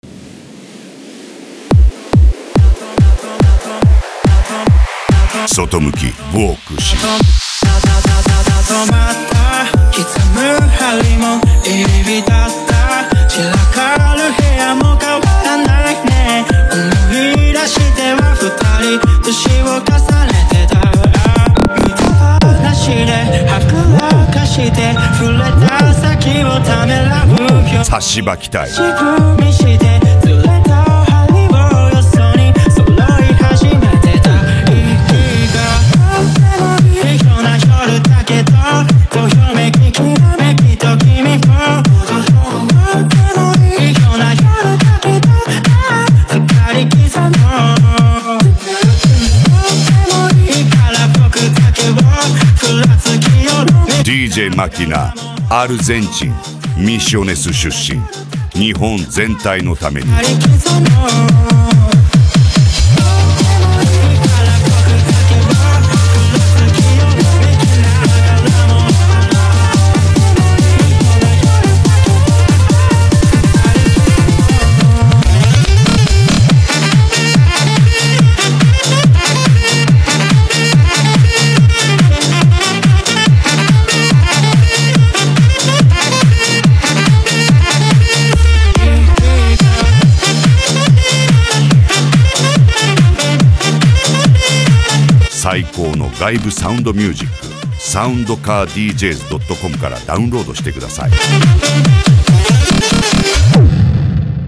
Hard Style
japan music
Minimal
PANCADÃO